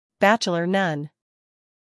英音/ ˈbætʃələ(r) / 美音/ ˈbætʃələr /
Either way, pronounce it like this: BATCH-uh-lur.